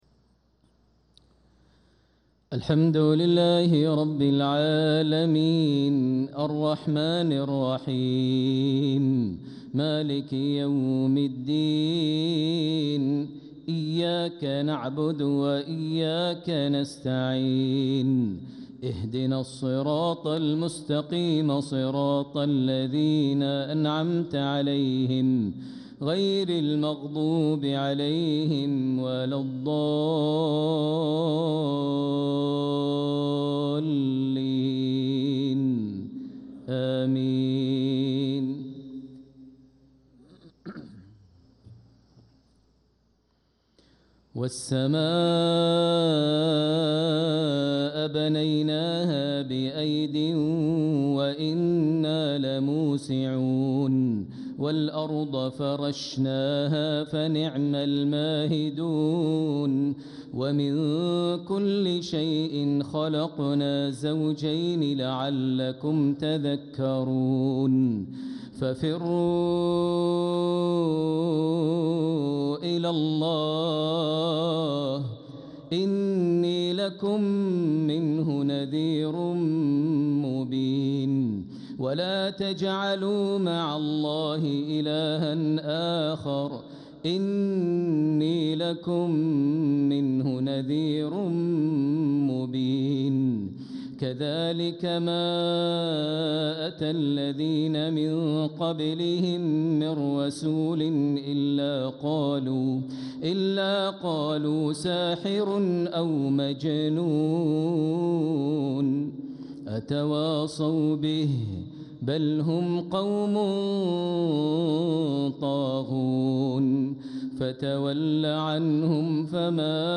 Maghrib prayer from Surat Adh-Dhariyat and An-Naziat | 5-5-2025 > 1446 H > Prayers - Maher Almuaiqly Recitations